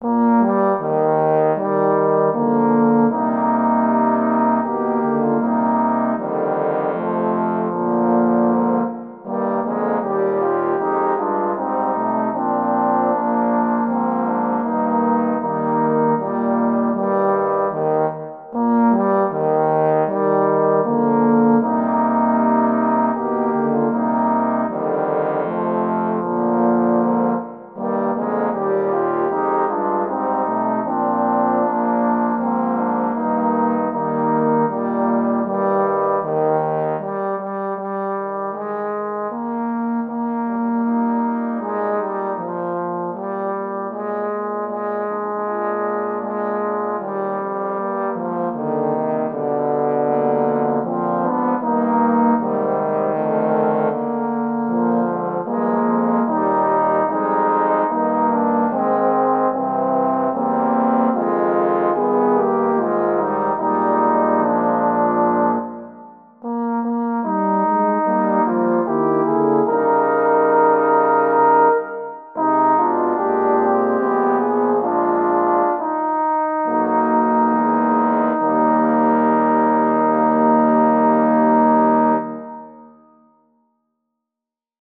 for Saxophone Quartet
Voicing: Saxophone Quartet (SATB)